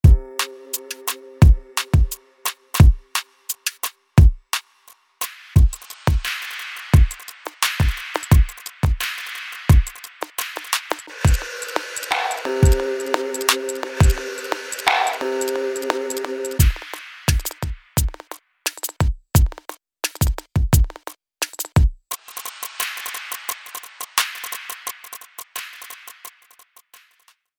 • 10 个循环
• 16 个单次采样（已映射到 MIDI 格式）